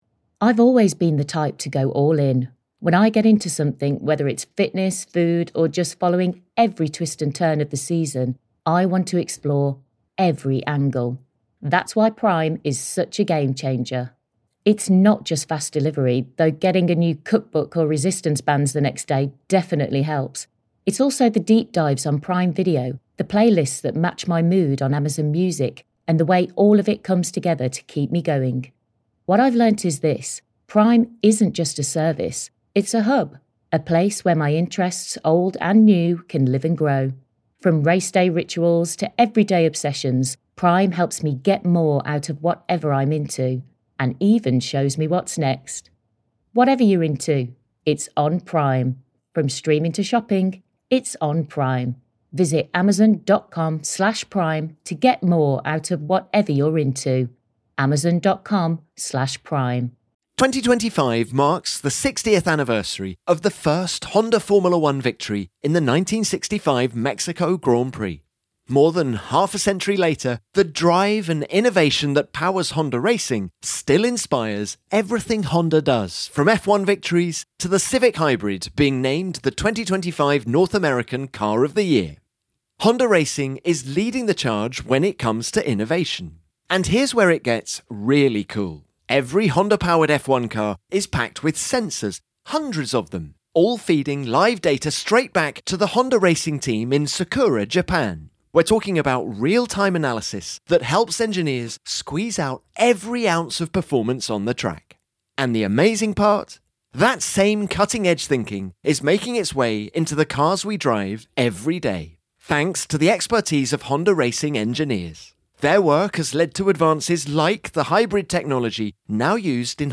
In the Budapest paddock